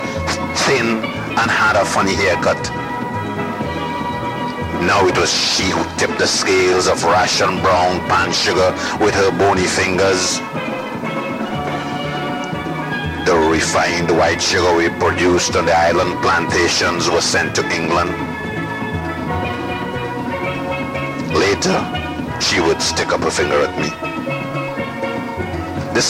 Short Story - My first Coca-Cola